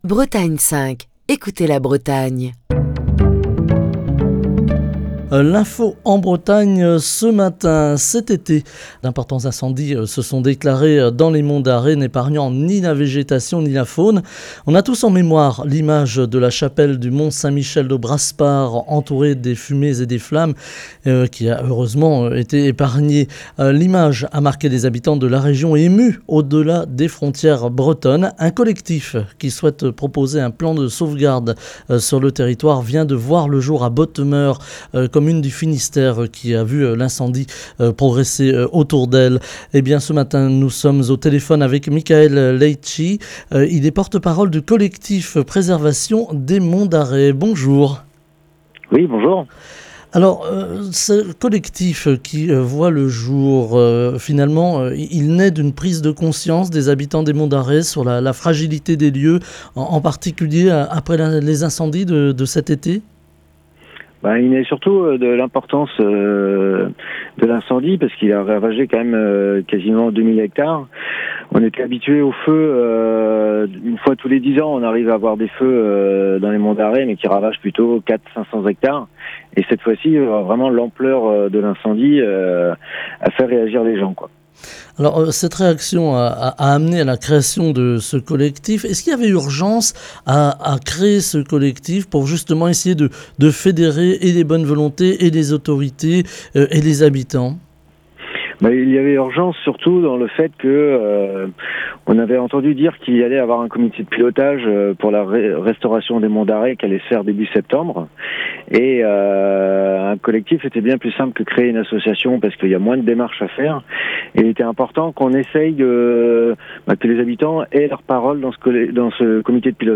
Émission du 30 août 2022. Cet été, d'importants incendies se sont déclarés dans les monts d'Arrée n'épargnant ni la végétation, ni la faune.